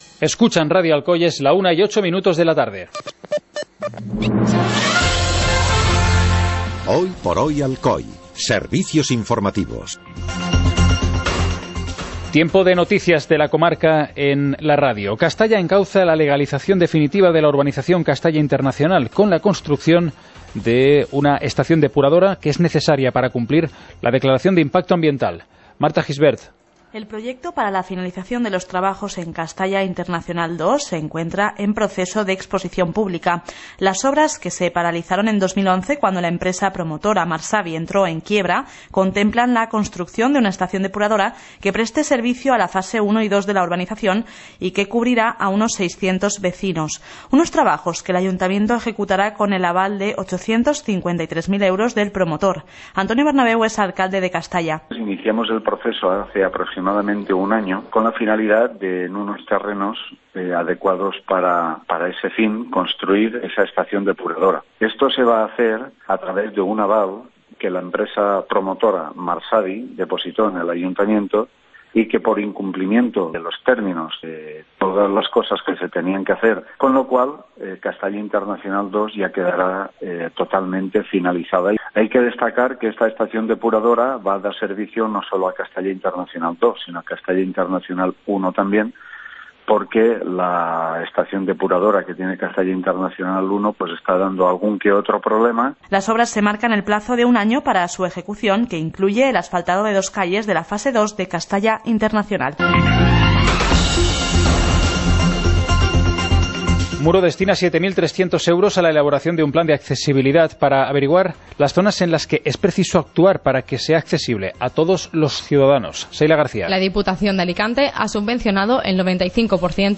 Informativo comarcal - jueves, 24 de agosto de 2017